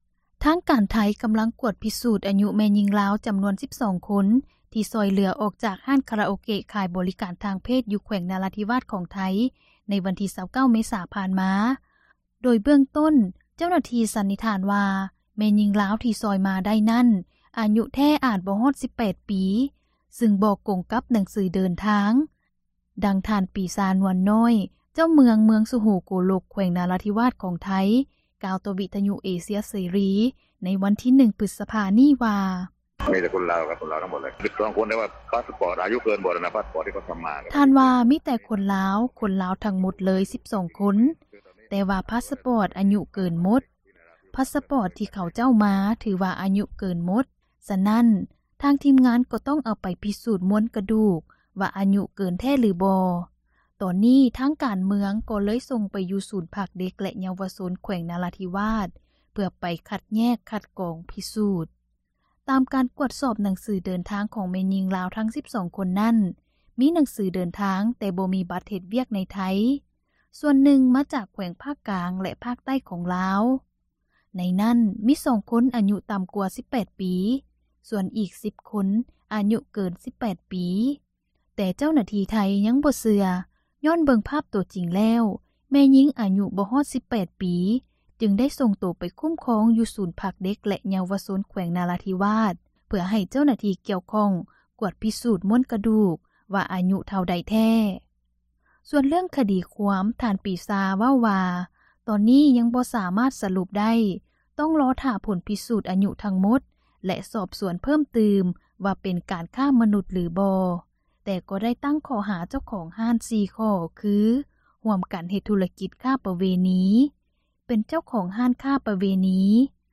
ທາງການໄທ ກໍາລັງກວດພິສູດອາຍຸ ຂອງແມ່ຍິງລາວ 12 ຄົນ ທີ່ຖືກຊ່ອຍເຫຼືອອອກຈາກ ຮ້ານຄາຣາໂອເກະ ຂາຍບໍຣິການ ທາງເພດ ຢູ່ແຂວງນາວາທິວາດ ຂອງໄທ ໃນວັນທີ 29 ເມສາ ຜ່ານມາ ໂດຍເບື້ອງຕົ້ນ ເຈົ້າໜ້າທີ່ ສັນນິຖານວ່າ ແມ່ຍິງລາວທີ່ຊ່ອຍມາໄດ້ນັ້ນ ອາຍຸແທ້ອາດບໍ່ຮອດ 18 ປີ ຊຶ່ງບໍ່ກົງກັບໜັງສືເດີນທາງ, ດັ່ງທ່ານປີຊາ ນວນນ້ອຍ ເຈົ້າເມືອງສຸໂຫງໂກລົກ ແຂວງນາຣາທິວາດ ຂອງໄທ ກ່າວຕໍ່ວິທຍຸເອເຊັຽເສຣີ ໃນວັນທີ 1 ພຶສພາ ນີ້ວ່າ: